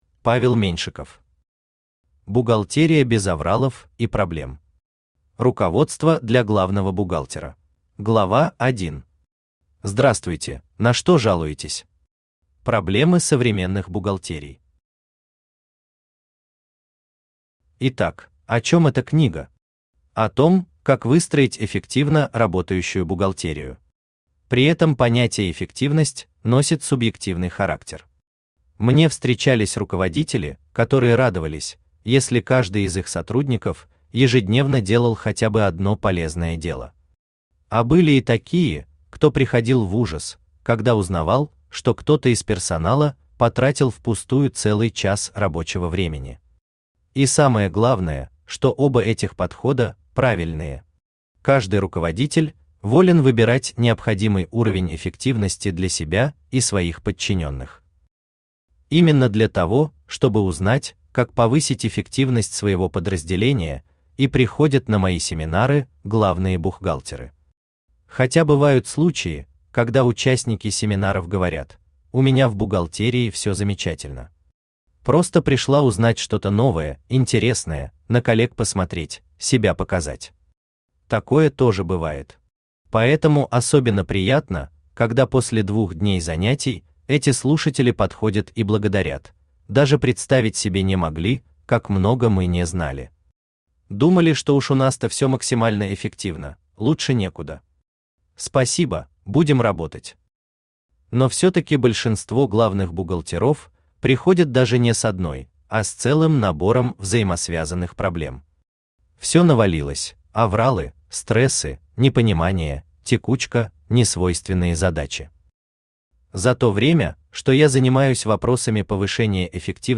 Аудиокнига Бухгалтерия без авралов и проблем. Руководство для главного бухгалтера | Библиотека аудиокниг
Руководство для главного бухгалтера Автор Павел Владимирович Меньшиков Читает аудиокнигу Авточтец ЛитРес.